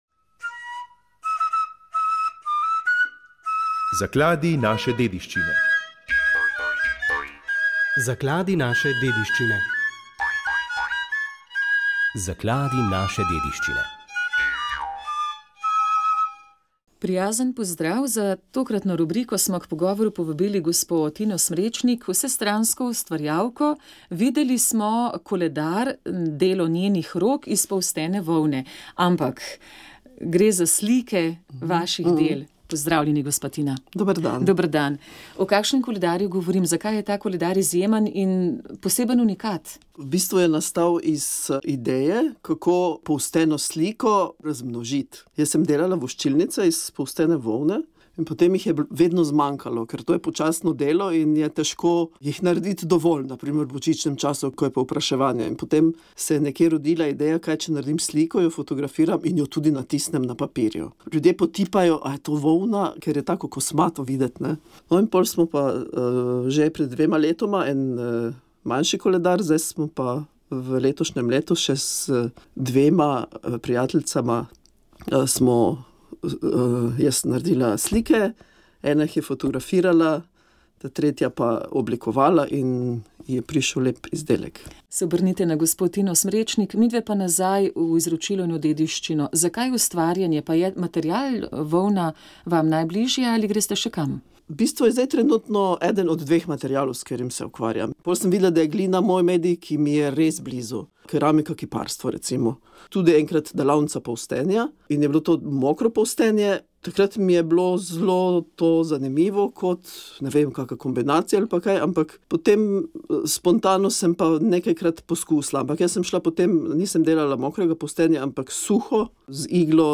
Sv. maša iz cerkve sv. Marka na Markovcu v Kopru 19. 1.
pel pa Ansambel sv. Marko.